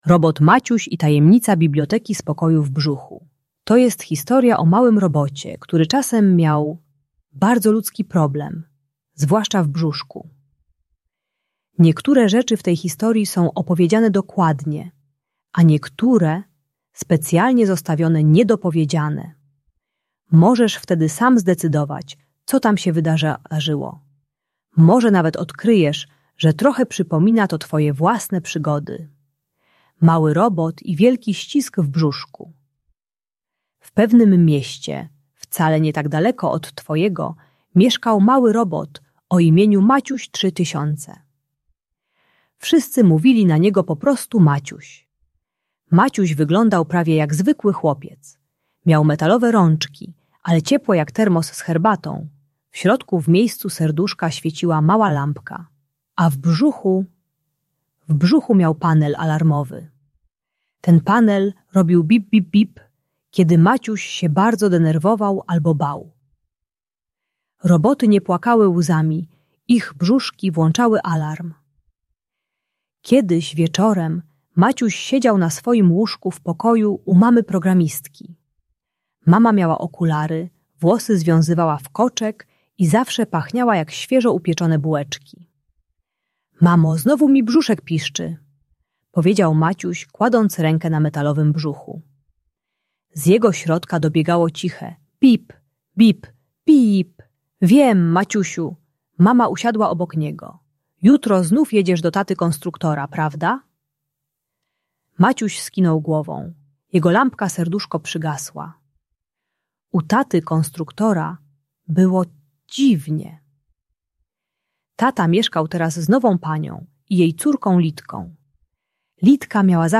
Robot Maciuś i Tajemnica Biblioteki Spokoju - Rozwód | Audiobajka